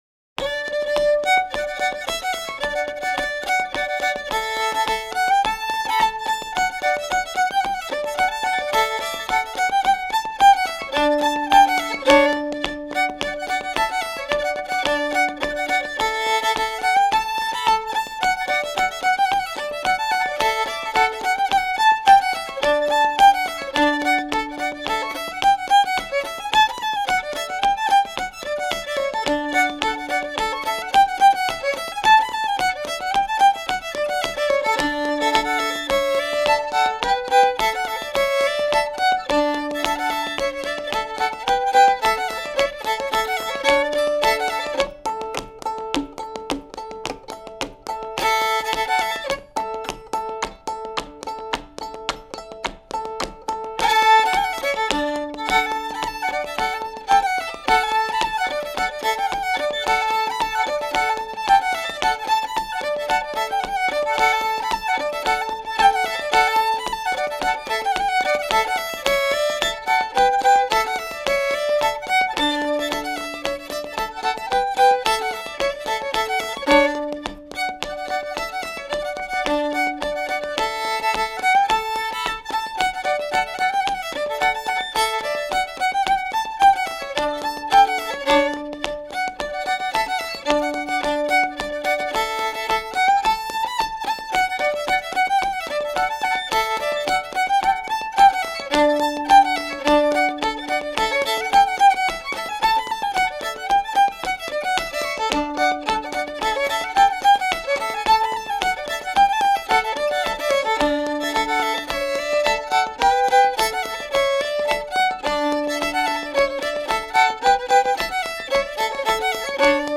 Localisation Québec (Plus d'informations sur Wikipedia)
Usage d'après l'analyste gestuel : danse ;
Genre laisse
Catégorie Pièce musicale éditée